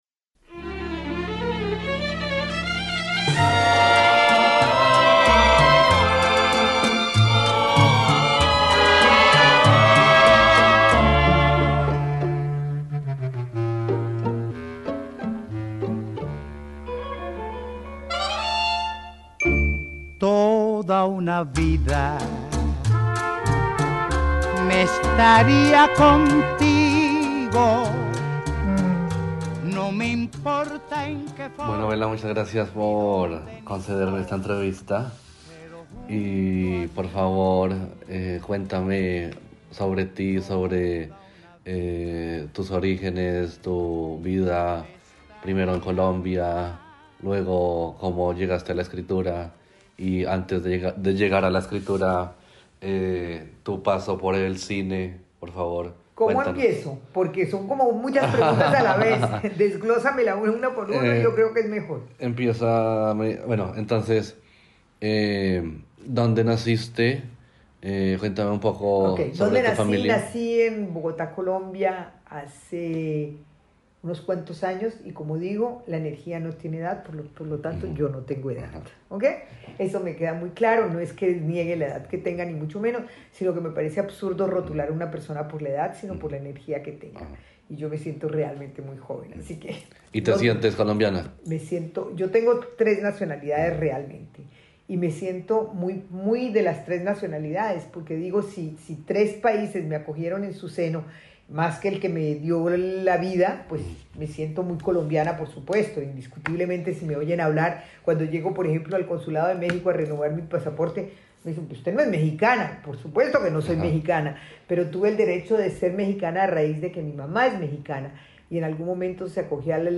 inicia una serie de entrevistas